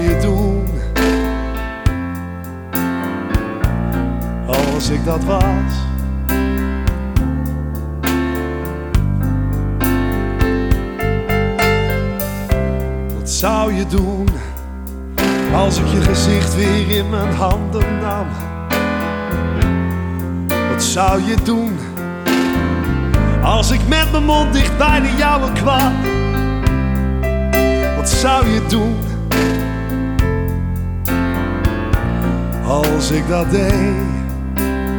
Жанр: Поп / Рок / Альтернатива